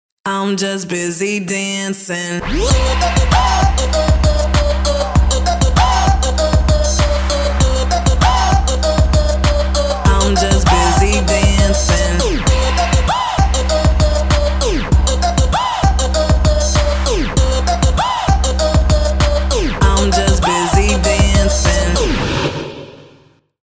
dance
Electronic